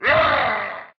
MonsterKilled.ogg